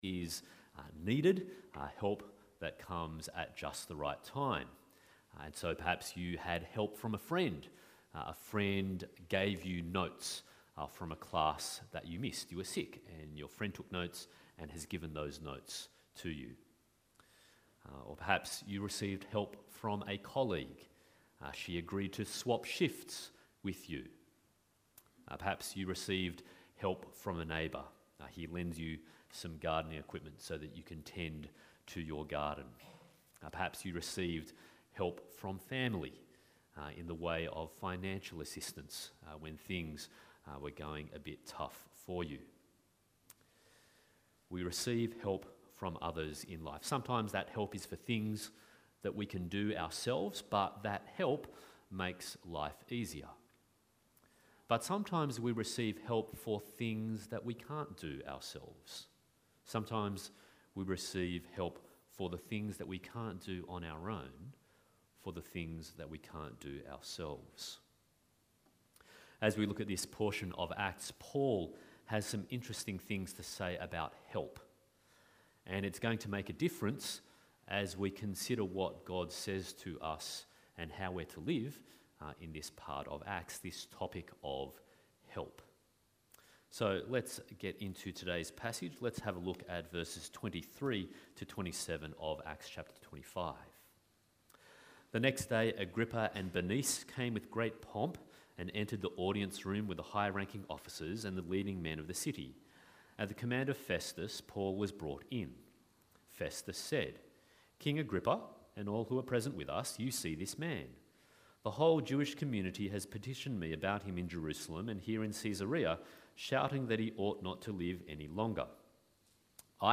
Acts 21-28 Passage: Acts 25:23-26:32, Deuteronomy 31:1-9, Matthew 28:16-20 Service Type: Sunday Morning